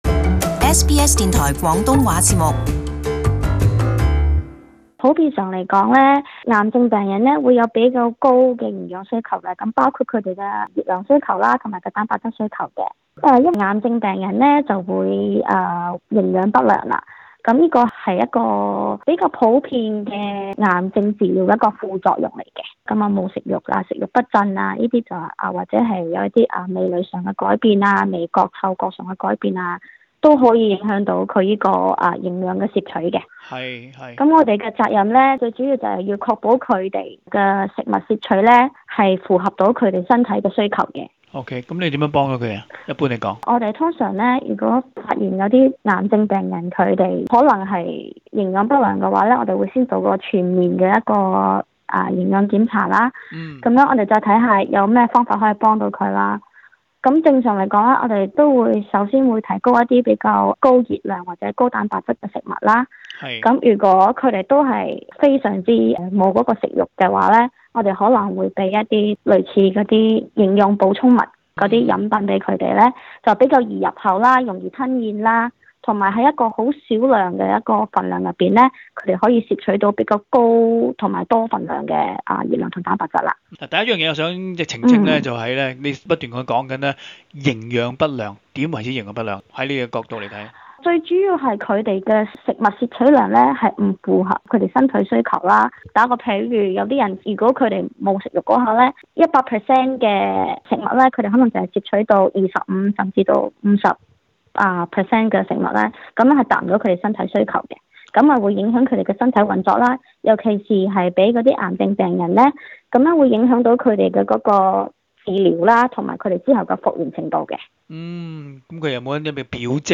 【社區專訪】